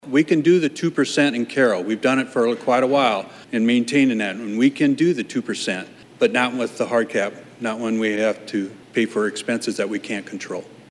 Carroll City Councilman LaVern Dirkx, who also serves on the Iowa League of Cities Executive Board, spoke before an Iowa House Committee on Tuesday as they hosted a public hearing on the Senate’s tax reform proposals.